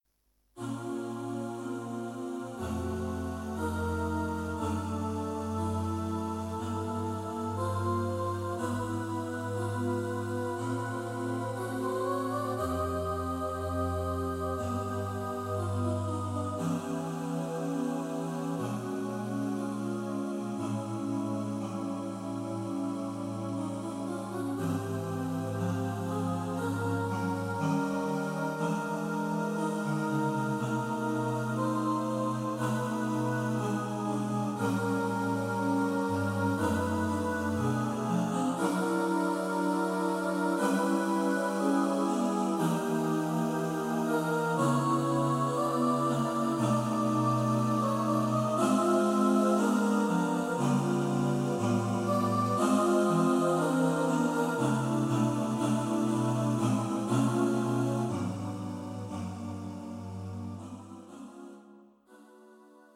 Choir (SATB)
This setting is for unaccompanied Choir